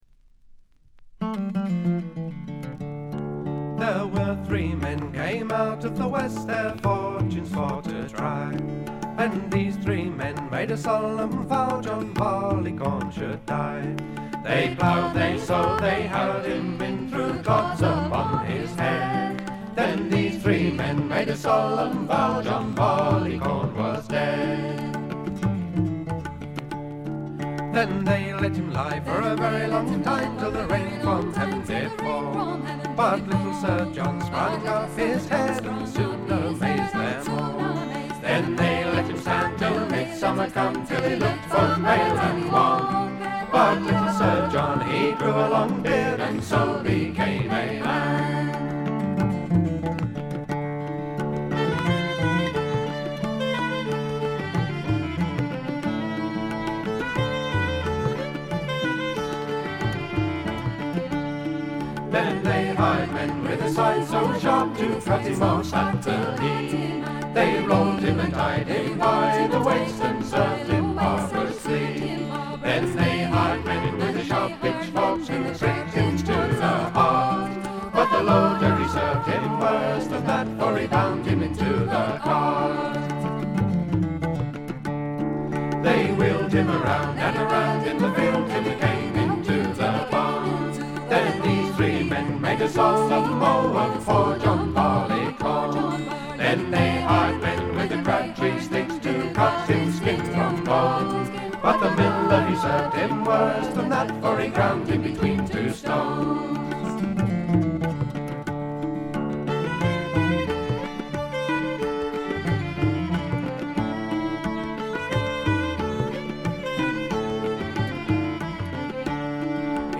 バックグラウンドノイズや軽微なチリプチは普通レベルで出ますが特筆するようなノイズはありません。
また専任のタブラ奏者がいるのも驚きで、全編に鳴り響くタブラの音色が得も言われぬ独特の味わいを醸しだしています。
試聴曲は現品からの取り込み音源です。